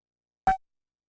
Yamaha CS30 L Angry Blip " Yamaha CS30 L Angry Blip F4 ( Angry Blip6732)
标签： MIDI-速度-32 FSharp4 MIDI音符-67 雅马哈-CS-30L 合成器 单票据 多重采样
声道立体声